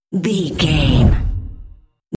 Deep whoosh pass by
Sound Effects
dark
tension
whoosh